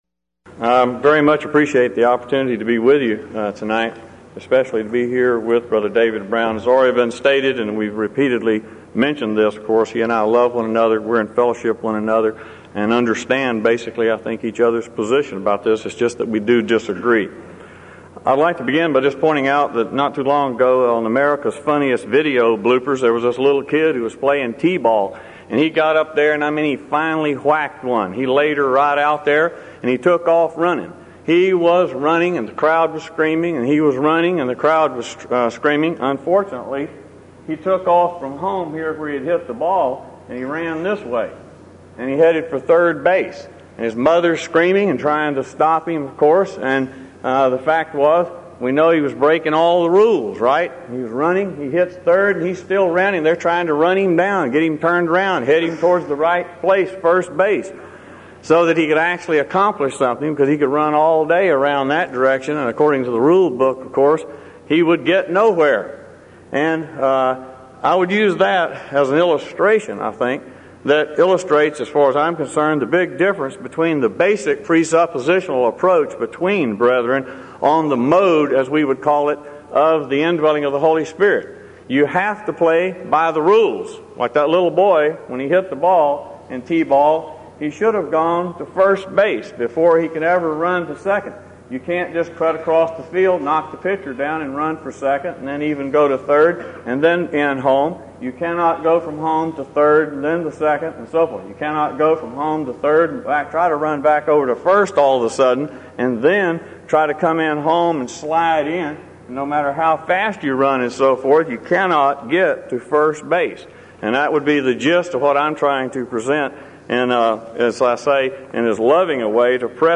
Event: 1996 Denton Lectures
lecture